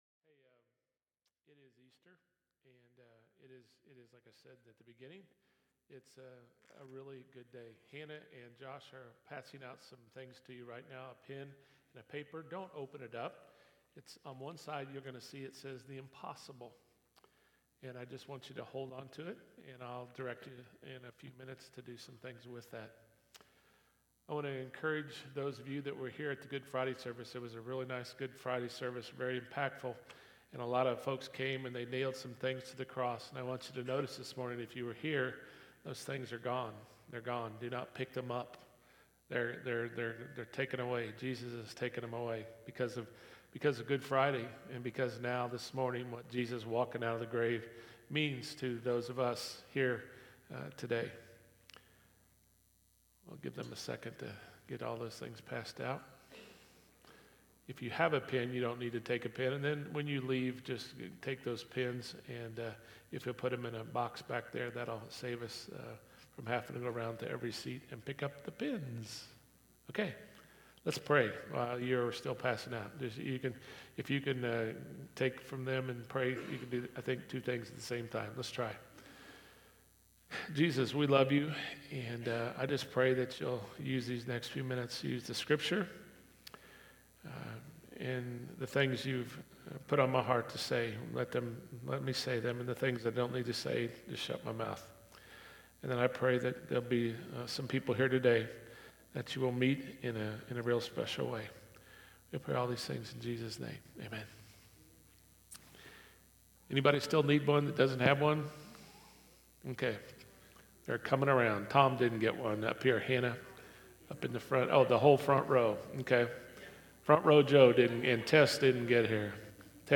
Easter Service 2024: The Gift Card